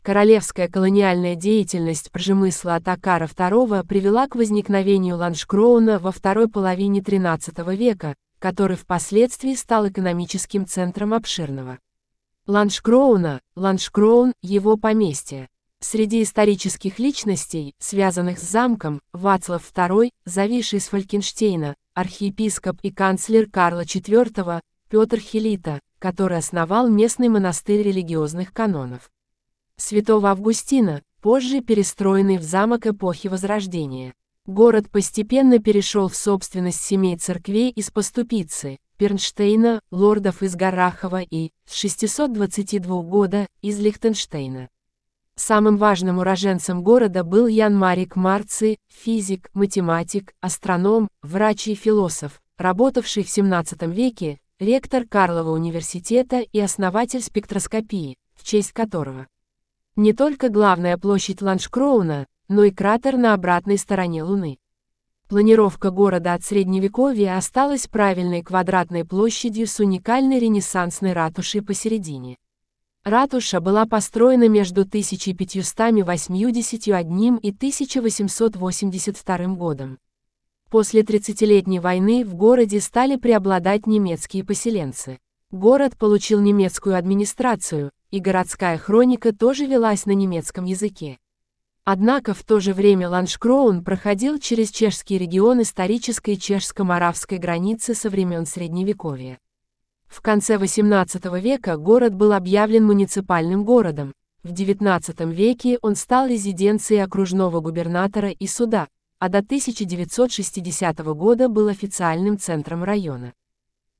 Презентация Ланшкроуна » разговорное слово на русском языке